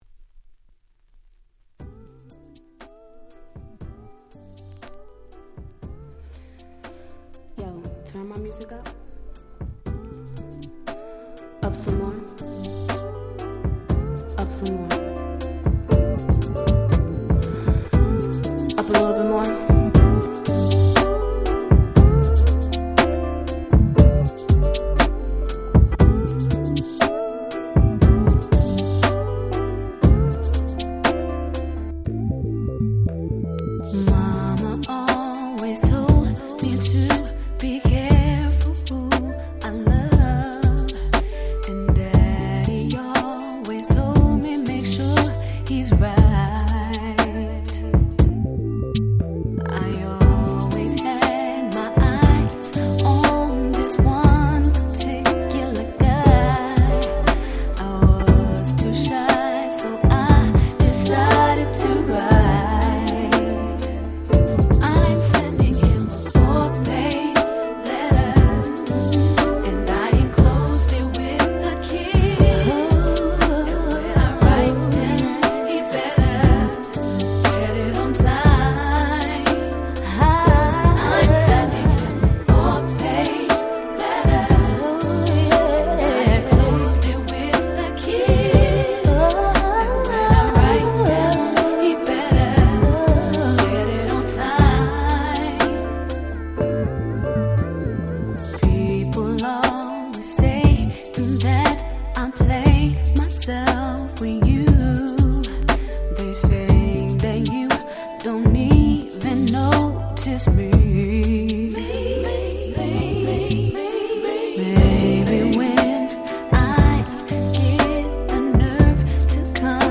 97' Smash Hit R&B !!